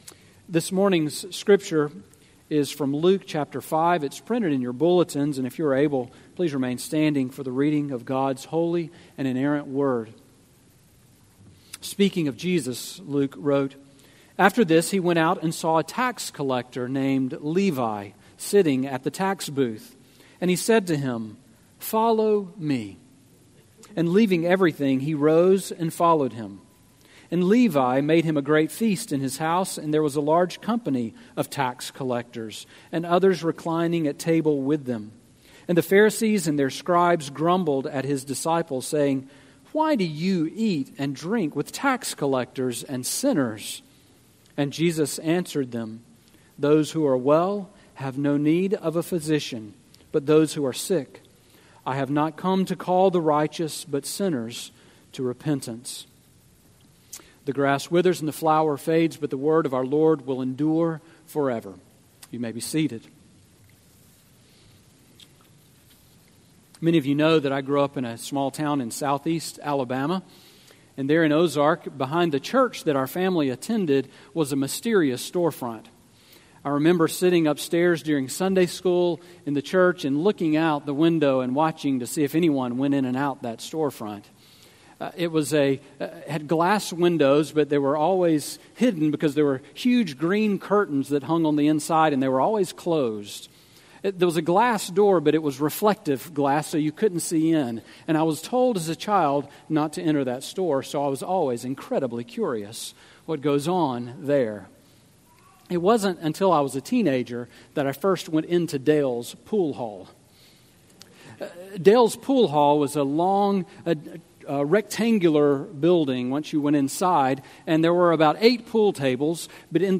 Sermon on Luke 5:27-32 from May 29